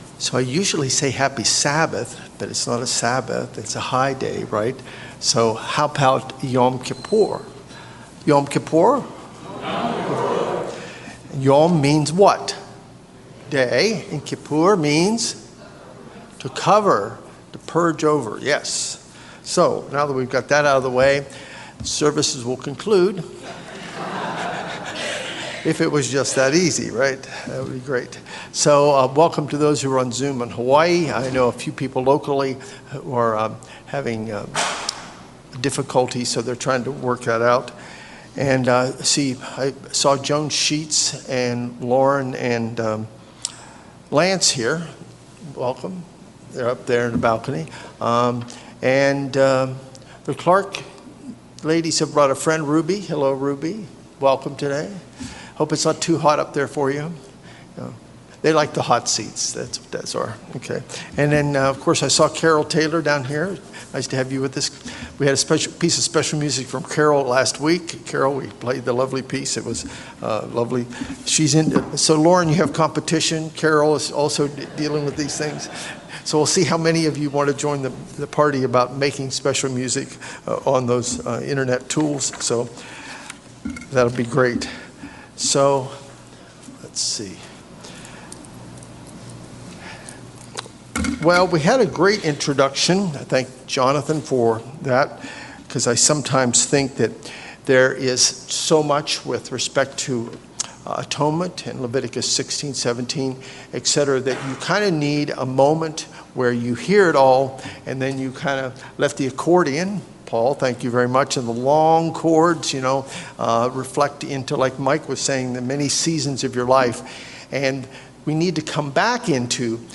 Given on the Day of Atonement 2025, this message analyzes the typologies of this important day in the Old Testament and explains the significance of Jesus Christ in fulfilling the role of High Priest as described in the Book of Hebrews.